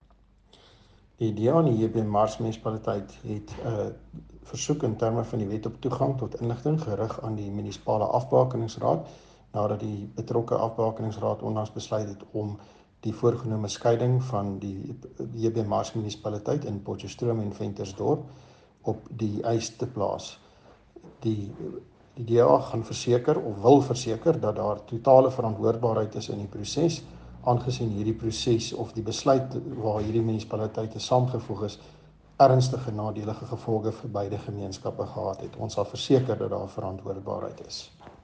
Note to Broadcasters: Please find linked soundbites in English and
Afrikaans by Cllr Hans-Jurie Moolman.